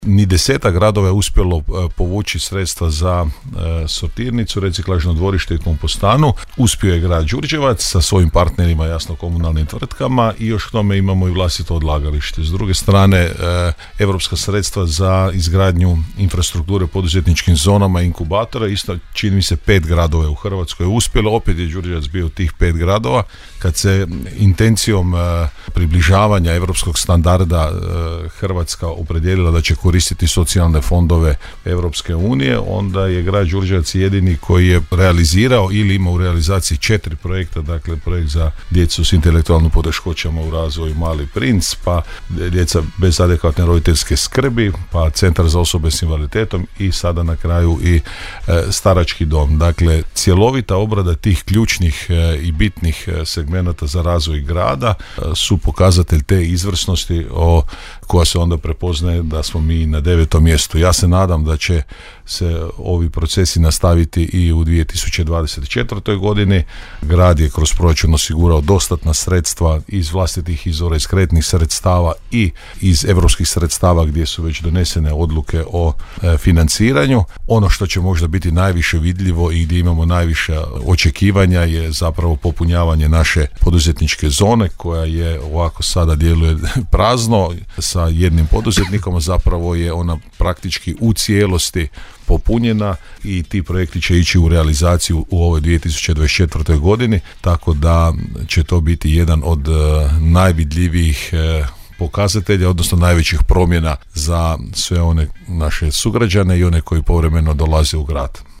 Prema podacima Ministarstva financija o konsolidiranim proračunima od 2014. do 2022. izdvojeni su hrvatski gradovi koji su u proteklih devet godina iskoristili najviše europskog novca za realizaciju svojih projekata. Na tom je popisu Grad Đurđevac zauzeo visoko deveto mjesto u konkurenciji cijele Hrvatske, a odlična pozicija ostvarena je s obzirom na ukupno povučena EU sredstva po glavi stanovnika u iznosu od 8.608,33 kuna, rekao je u emisiji Gradske teme, gradonalčelnik Grada Đurđevca, Hrvoje Janči;